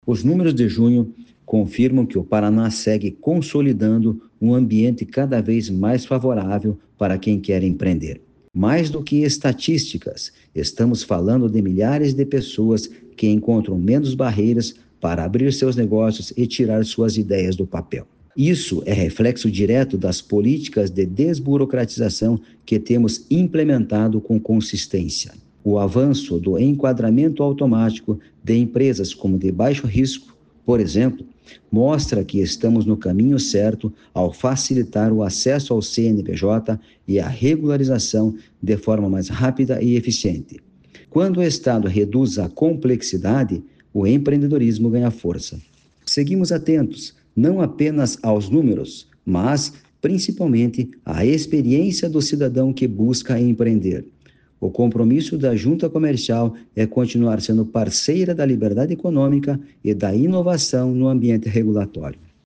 Sonora do presidente da Jucepar, Marcos Rigoni, sobre o crescimento no saldo de empresas no primeiro semestre de 2025